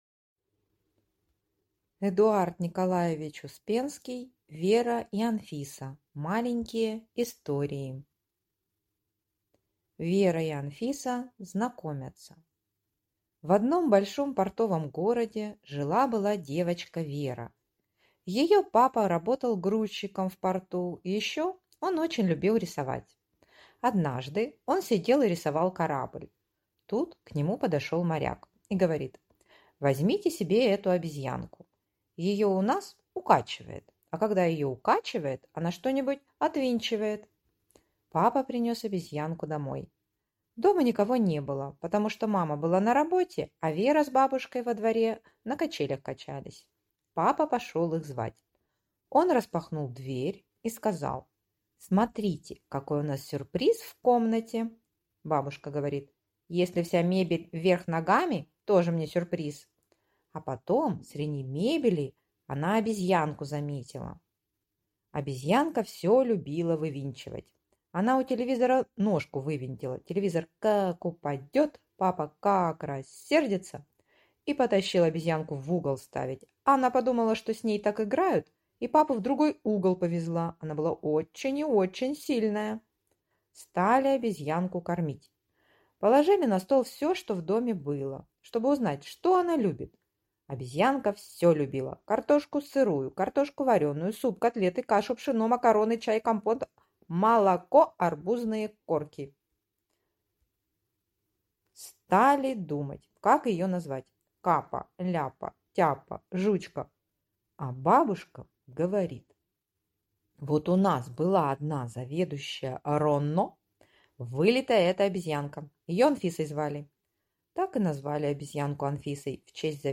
Аудиокнига Вера и Анфиса. Маленькие истории (сборник) | Библиотека аудиокниг